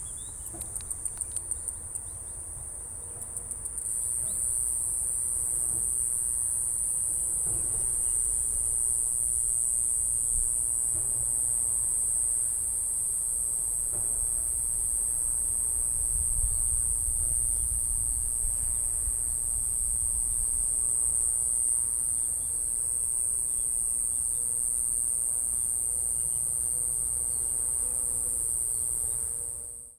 イワサキクサゼミの鳴き声
イワサキクサゼミは葉上でジージージーと鳴いています。体長15mmほどで日本最小のセミです。
＊ 沖縄の動物・植物達のコーナー 録音：SonyリニアPCMレコーダーPCM-M10 南城市にて録音
iwasakikusazemi-call.mp3